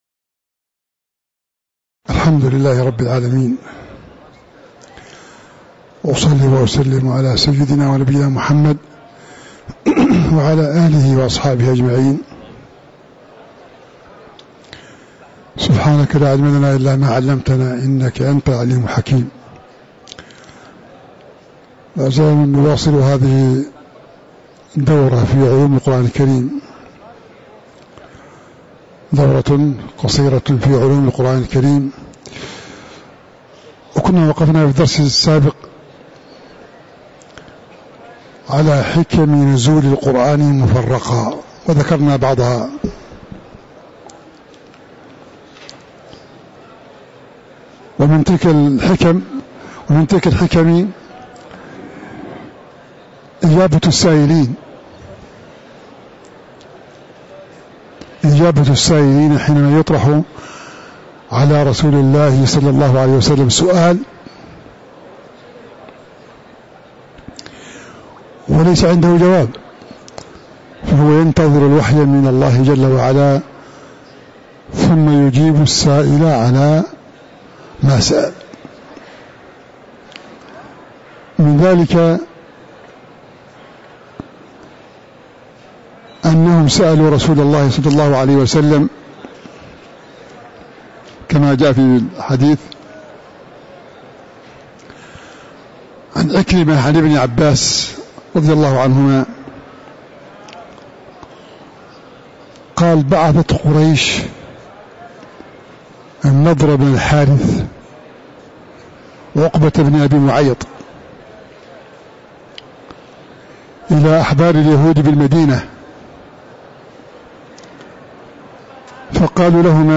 تاريخ النشر ٢٥ محرم ١٤٤٥ هـ المكان: المسجد النبوي الشيخ